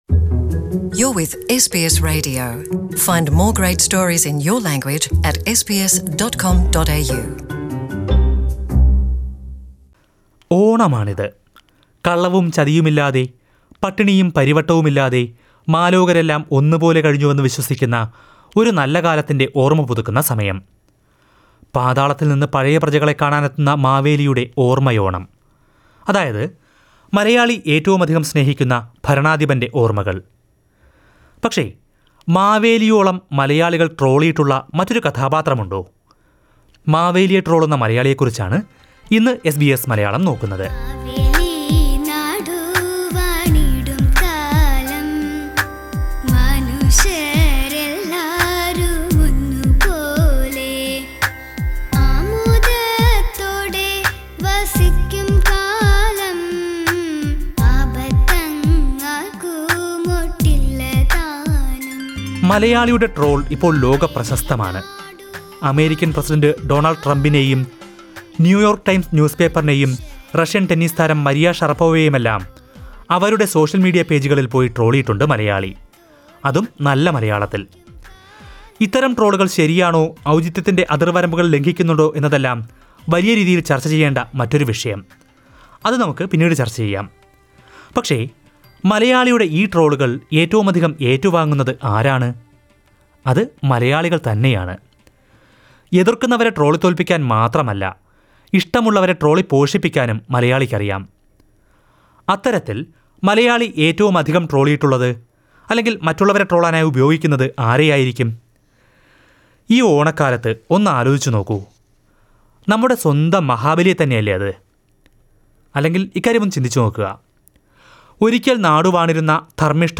But what imagery do we have about Maveli? Listen to this report...